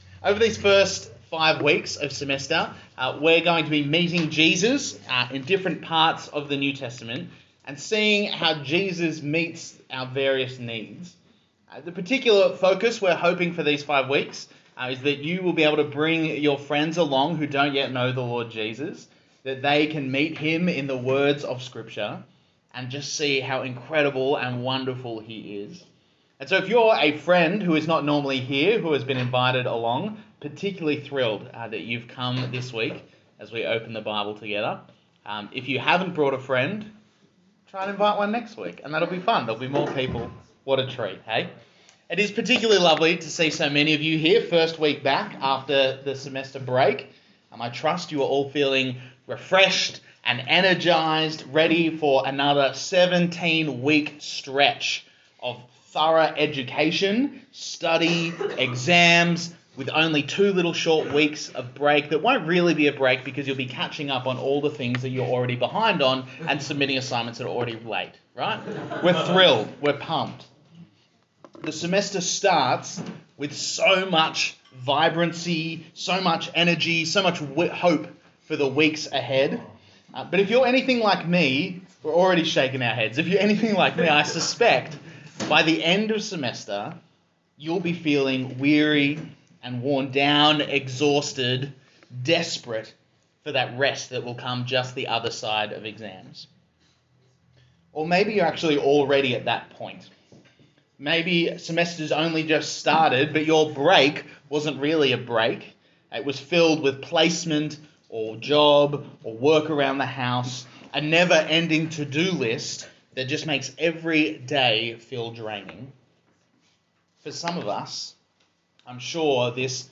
Meet Jesus Passage: Matthew 11:25-12:8 Talk Type: Bible Talk « The Triumph of the Cross